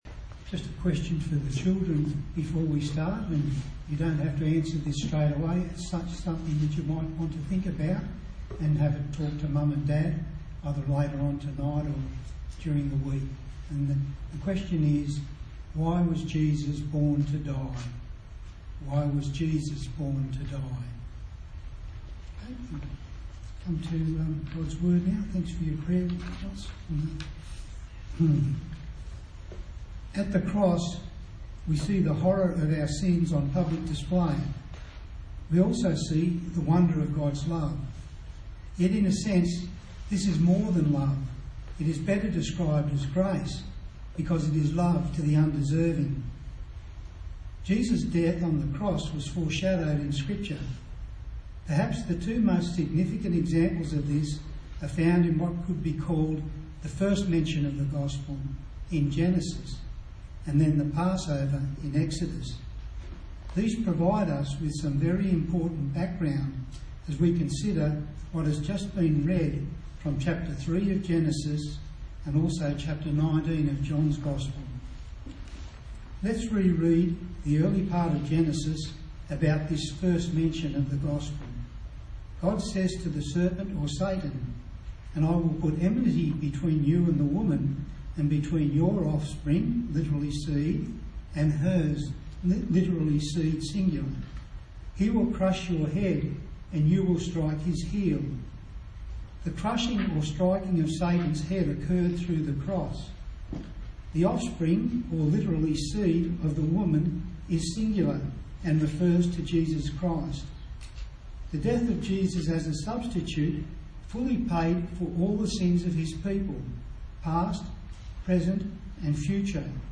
A sermon in the series on the book of Genesis